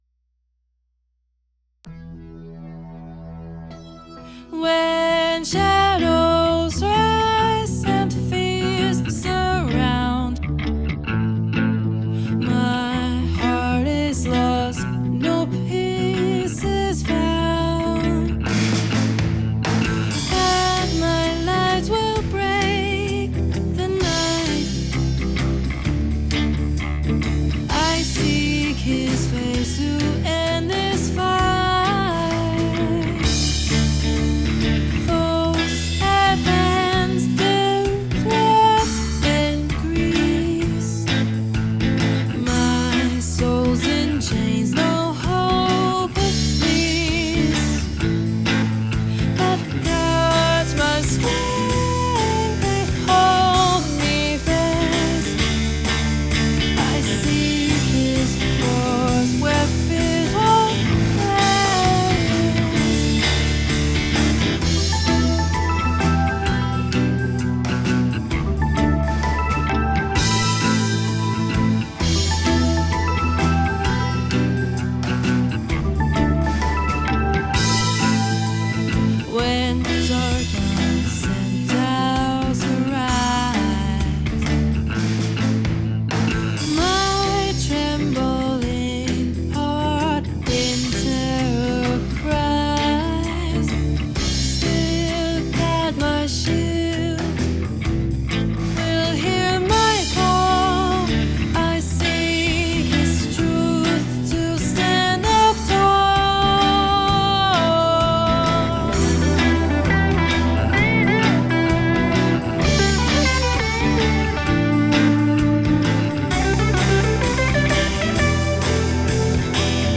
ACE Studios AI Voice.
Progressive Rock with Electronic Music.
Guitar and Bass by hand.